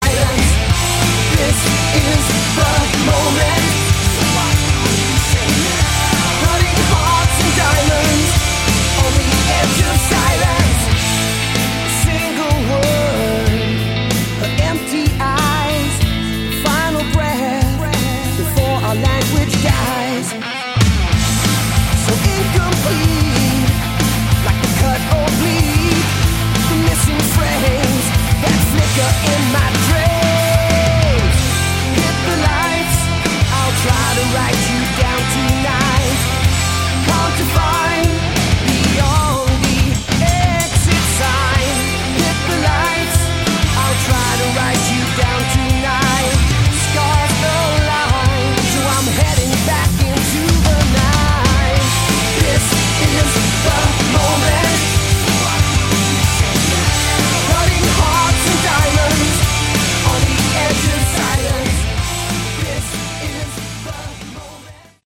Category: Hard Rock
guitar
vocals
bass
drums